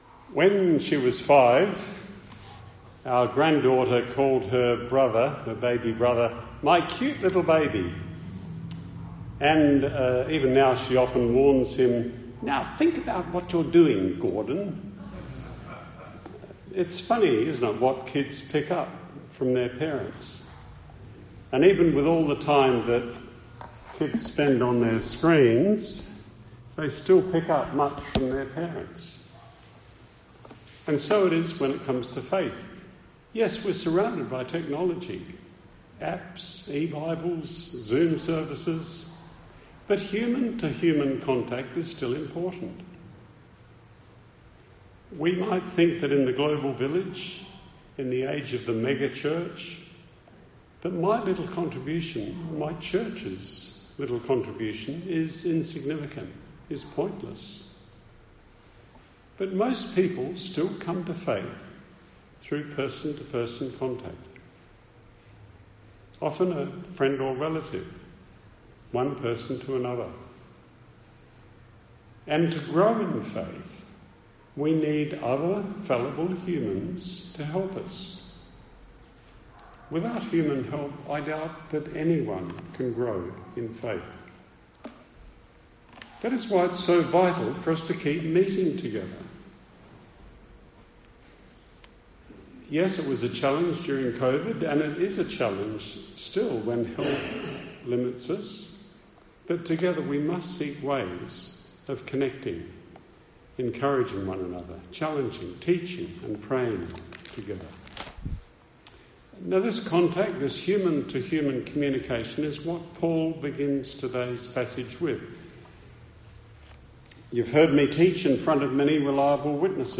Sermon at the Christian Mission